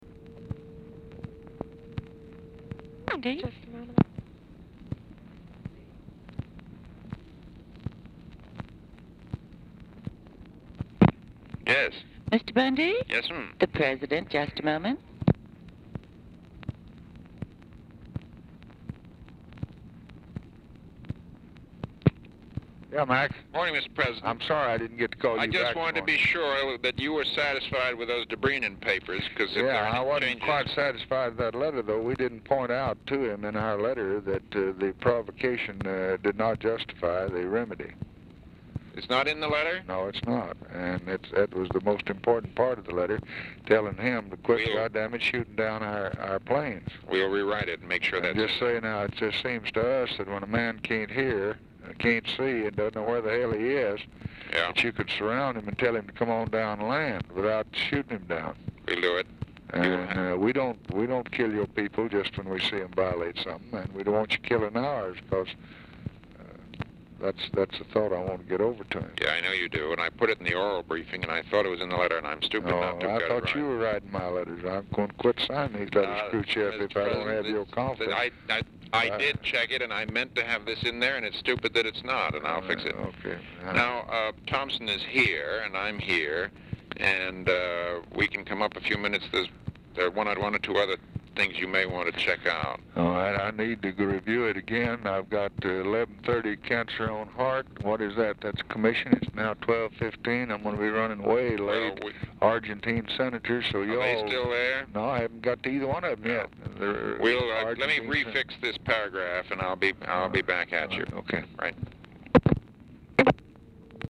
Telephone conversation # 3051, sound recording, LBJ and MCGEORGE BUNDY, 4/17/1964, 12:14PM
Format Dictation belt